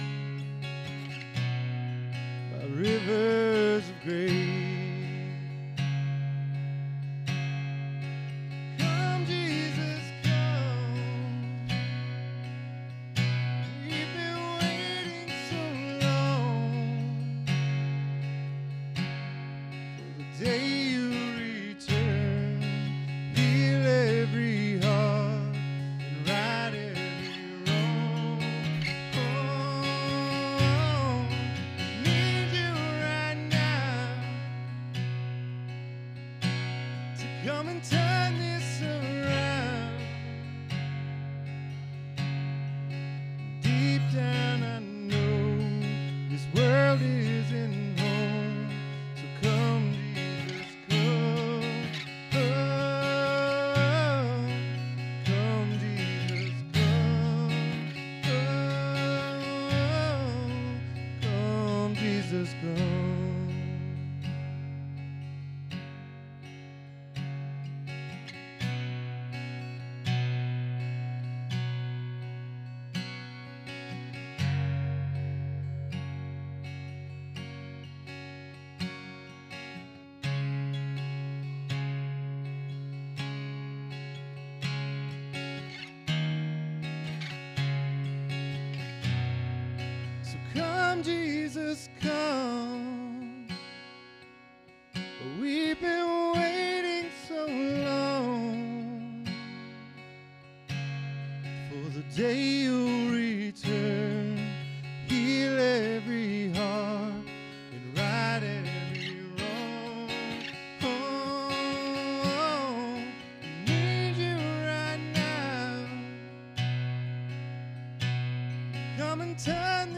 SERMON DESCRIPTION "Dare to Go" explores the journey of Abram as he answers God’s call to step out in faith.